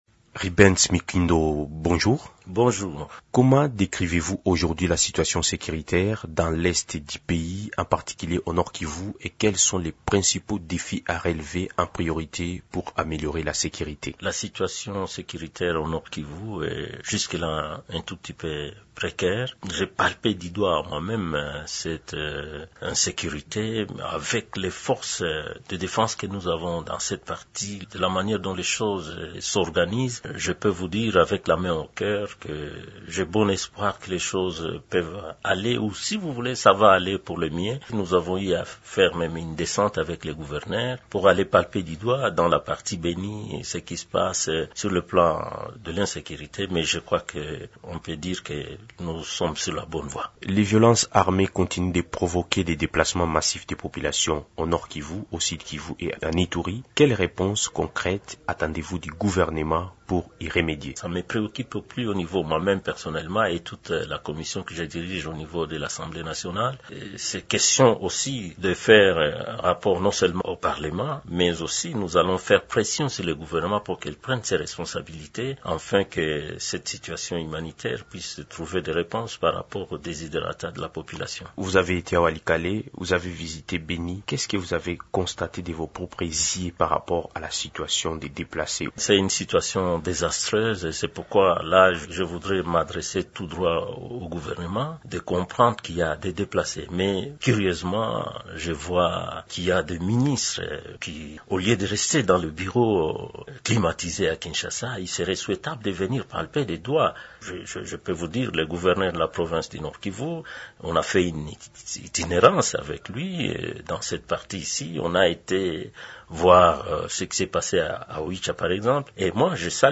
Invité de Radio Okapi ce mercredi 10 septembre, il dresse un tableau préoccupant de la situation sécuritaire et humanitaire dans la province, qu’il qualifie de « précaire ».